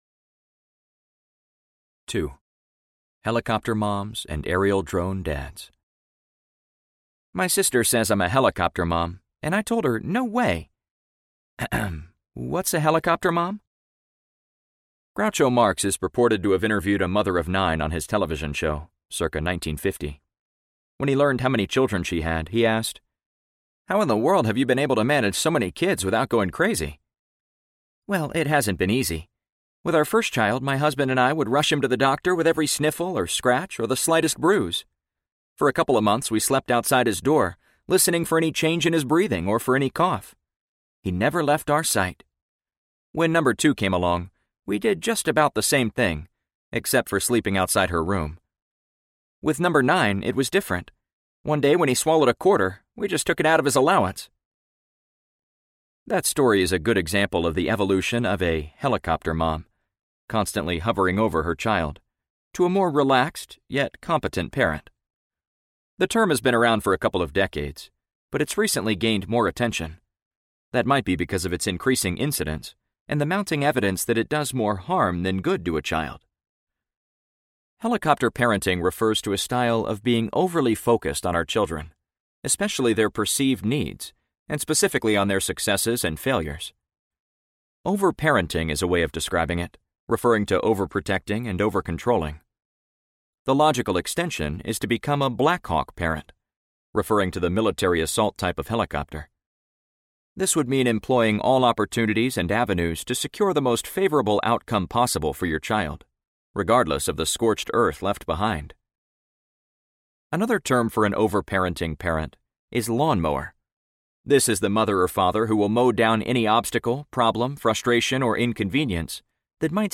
Ask the Family Doctor Audiobook